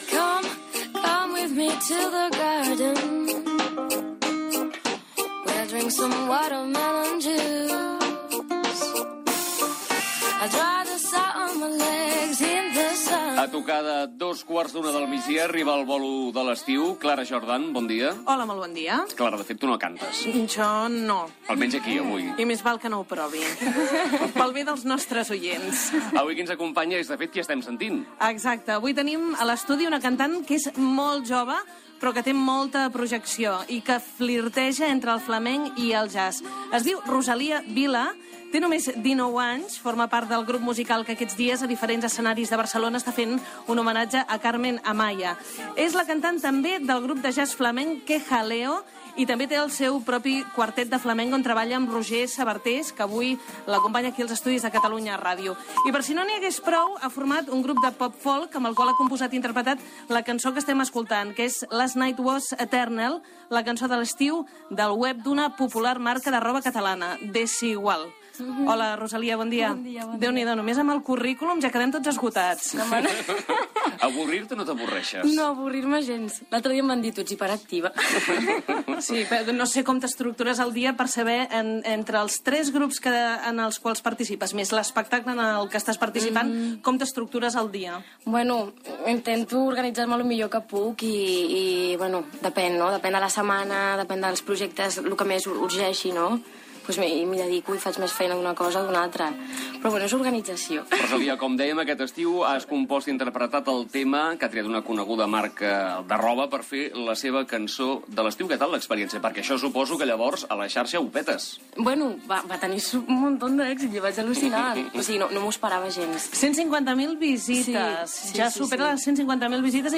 entrevista i interpretació d'un parell de temes a l'estudi
Info-entreteniment